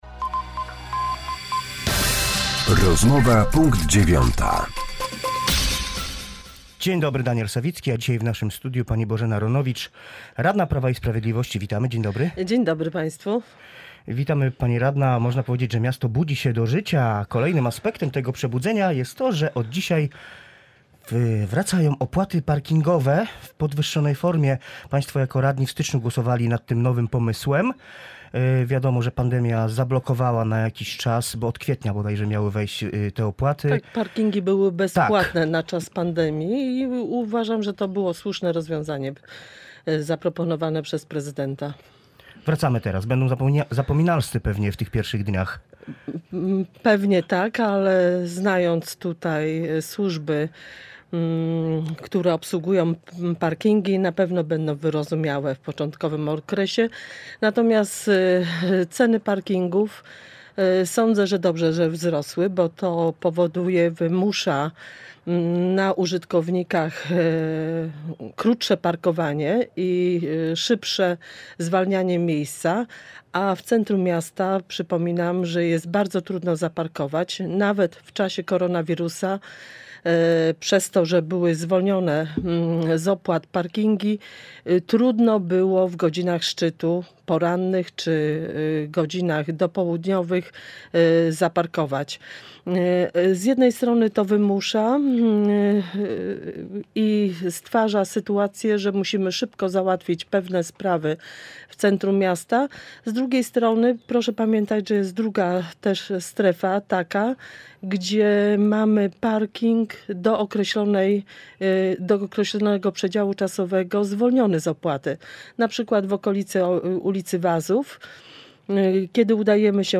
Z radną klubu Prawo i Sprawiedliwość rozmawia